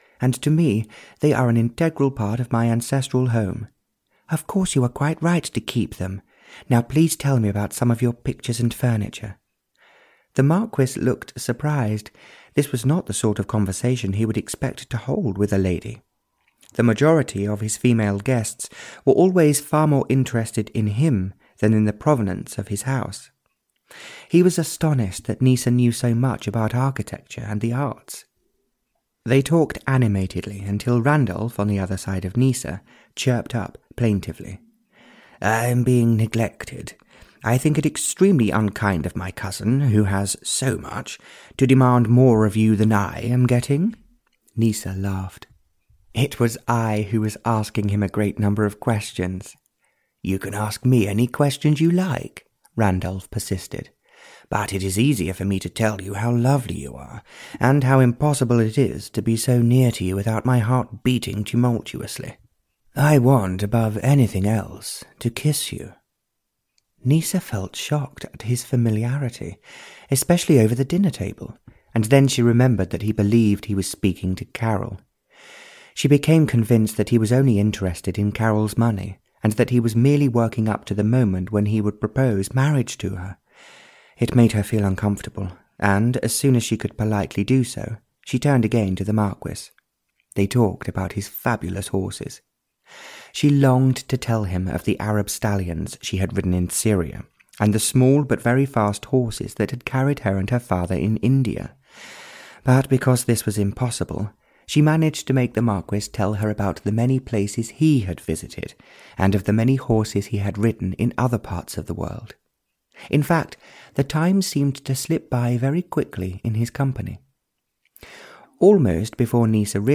Ukázka z knihy
tangled-hearts-en-audiokniha